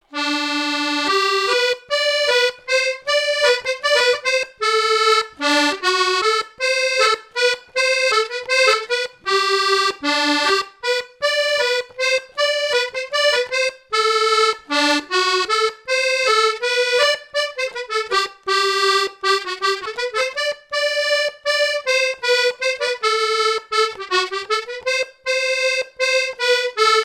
danse : java
Répertoire à l'accordéon diatonique
Pièce musicale inédite